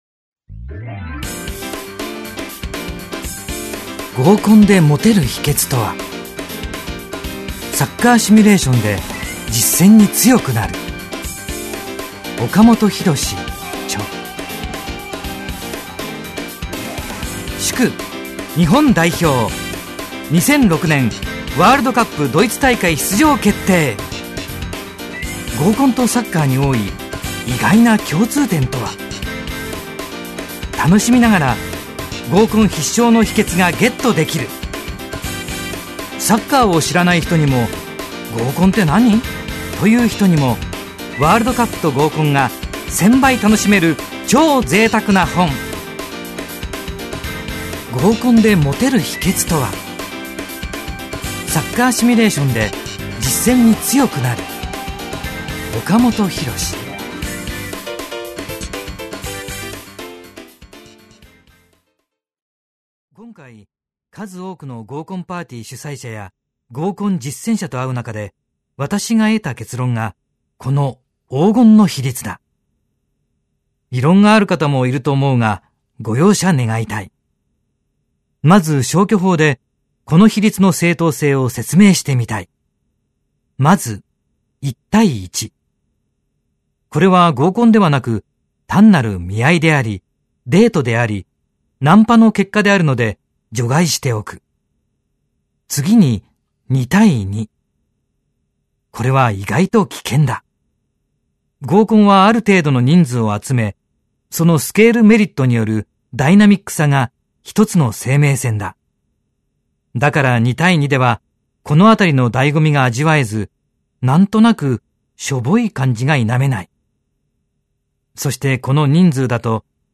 [オーディオブック] 合コンでモテる秘訣とは